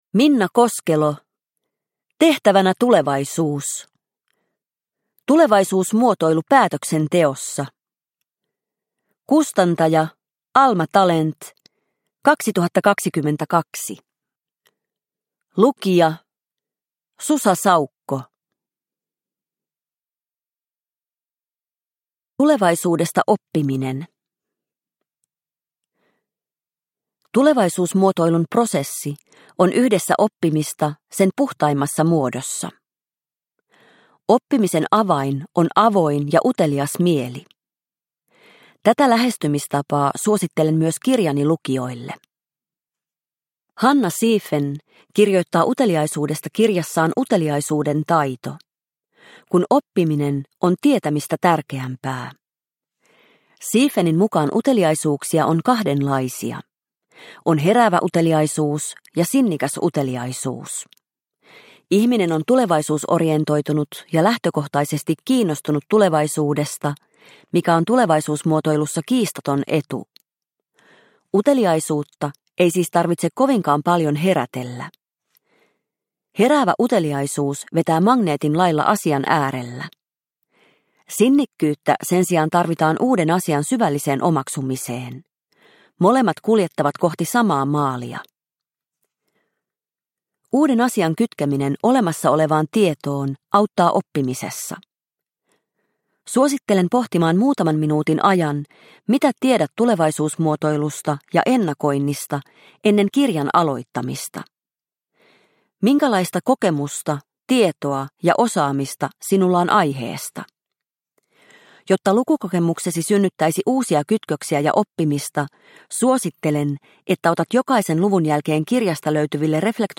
Tehtävänä tulevaisuus – Ljudbok – Laddas ner